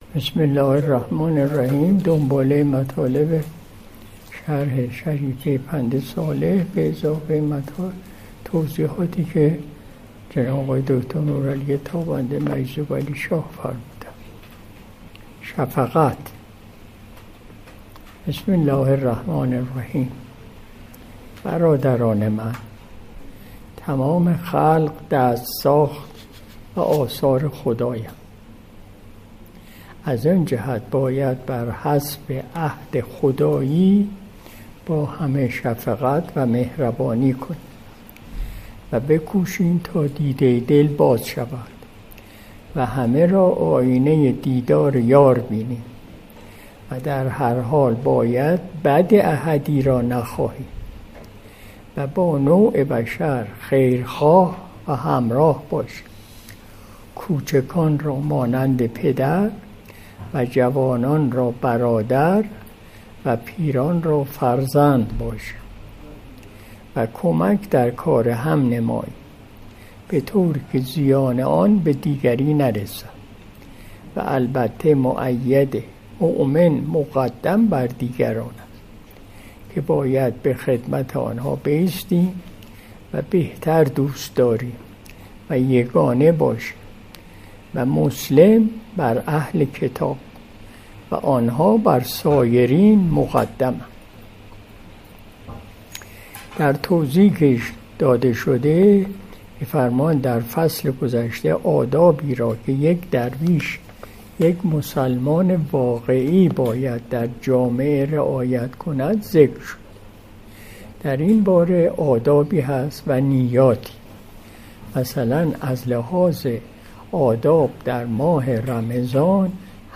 مجلس صبح پنجشنبه ۲۲ تیر ماه ۱۴۰۲ شمسی